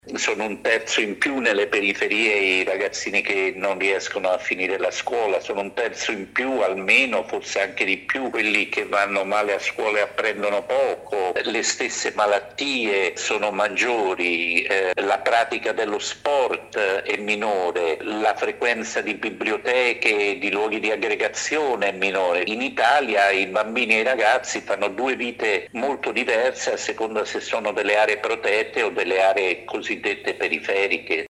Disparità di accesso – Con i Bambini ha presentato il nuovo report su giovani e periferie. Ascoltiamo il presidente Marco Rossi Doria.